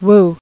[woo]